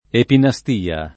[ epina S t & a ]